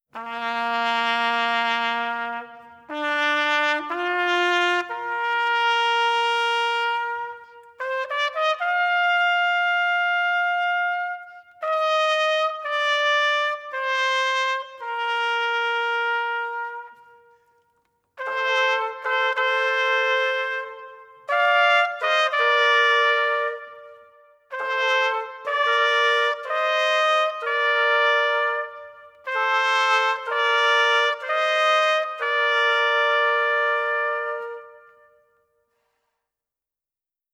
Santa Tecla Tarragona